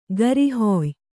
♪ garihoy